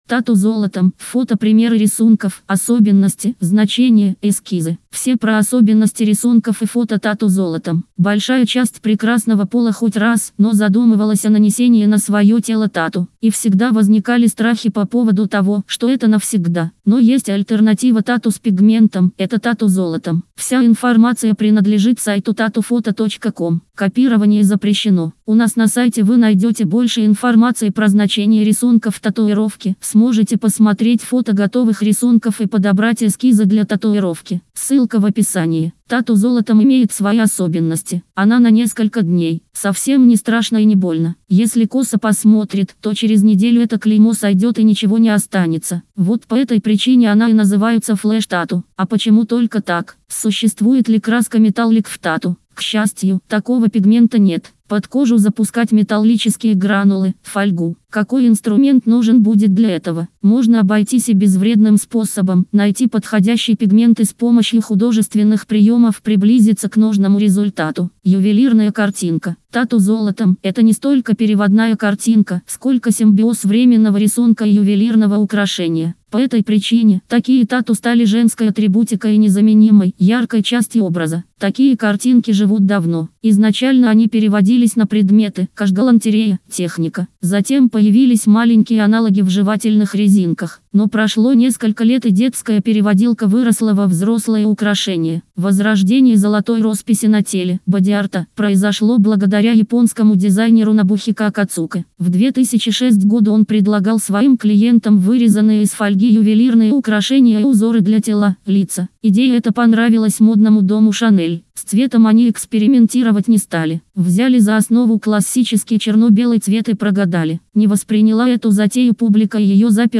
Тату-золотом-–-аудио-версия-статьи-для-сайта-tatufoto.com_.mp3